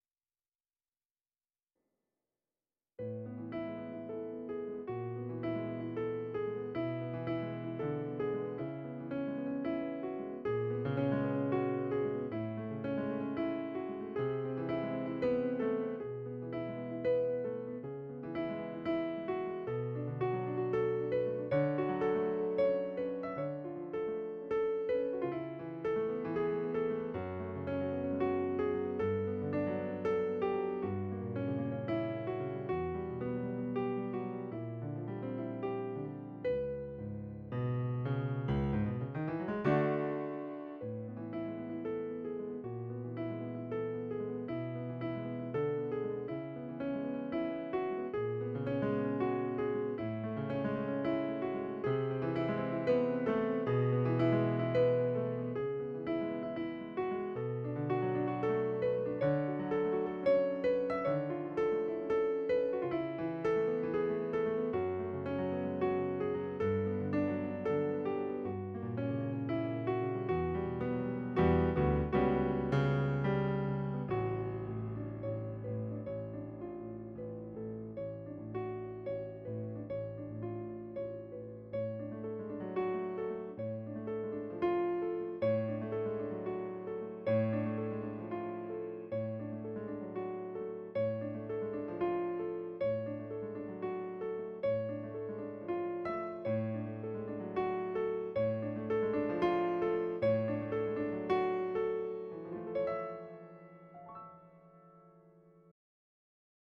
Acrono: un análisis de la conjunción entre indie, balada jazz y diégesis cinematográfica en un contexto pianístico de nostalgia lírica
Nostalgia, Apoyo visual, Aspectos técnico-musicales, Emoción, Onirismo, Recursos creativos, Indie, Balada Jazz, Diégesis musical